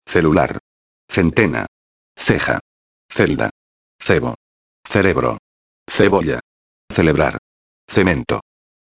Fonética: Exemplos:
» O "c" (la ce), quando acompanhado de "e" ou "i" (exemplo: ce, ci), pronuncia-se, só na Espanha, como o " th" do inglês na palavra "with".